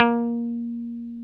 Index of /90_sSampleCDs/Roland L-CDX-01/GTR_Dan Electro/GTR_Dan-O 6 Str